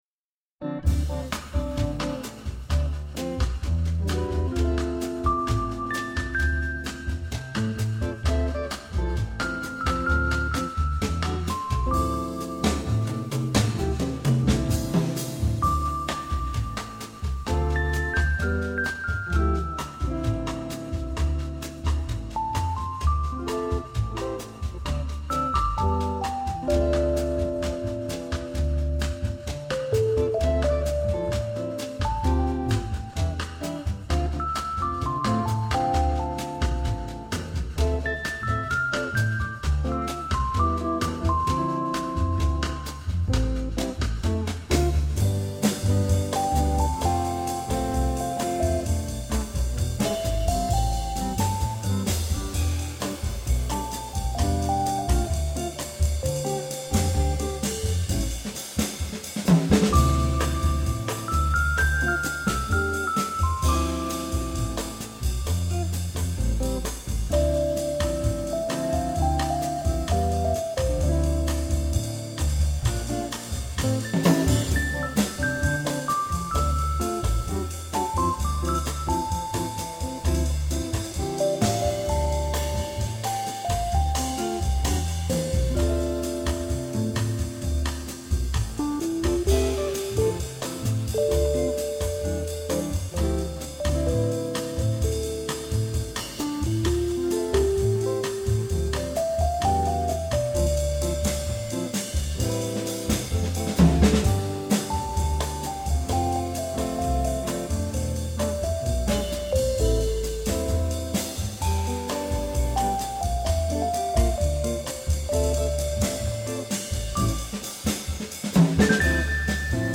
Light and airy, this jazzy samba is like floating on
A slinky jazzy bass line, a little piano, a flugelhorn solo, with a bit of brass to back it all up.